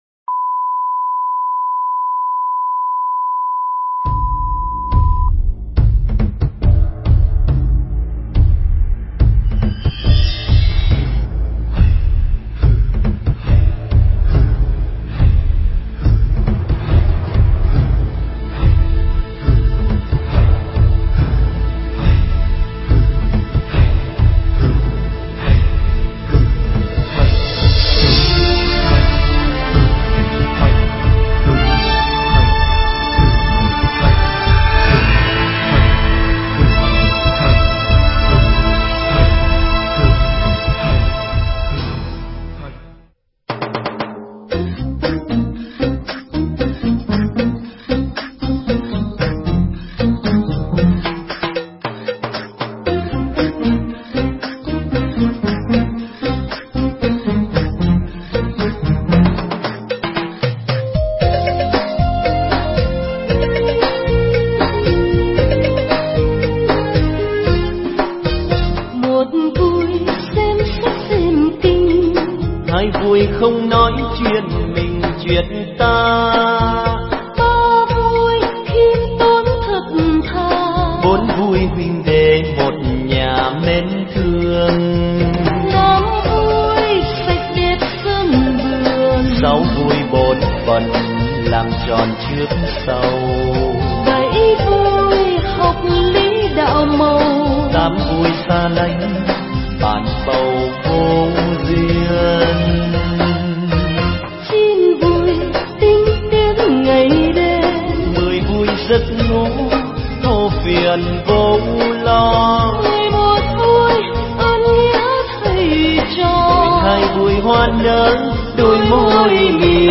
Nghe Mp3 thuyết pháp Vững Tâm Cuộc Đời
Mp3 pháp thoại Vững Tâm Cuộc Đời